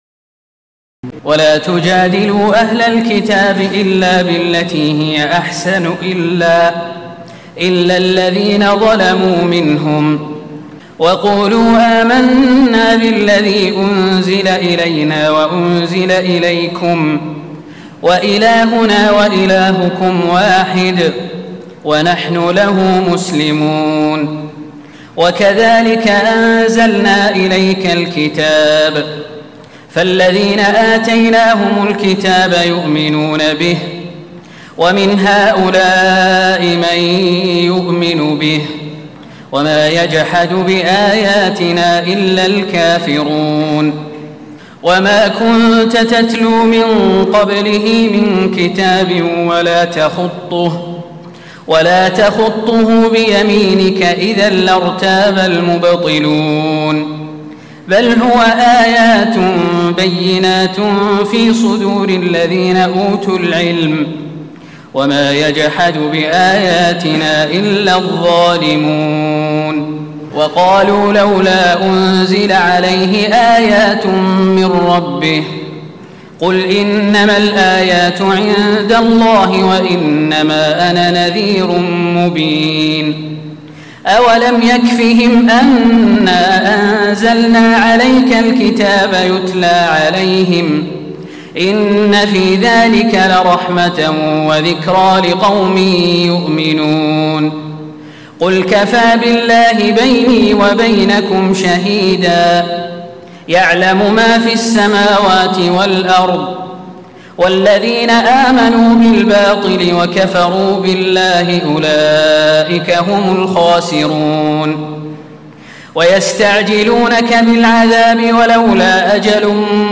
تراويح الليلة العشرون رمضان 1436هـ من سور العنكبوت (46-69) و الروم و لقمان (1-11) Taraweeh 20 st night Ramadan 1436H from Surah Al-Ankaboot and Ar-Room and Luqman > تراويح الحرم النبوي عام 1436 🕌 > التراويح - تلاوات الحرمين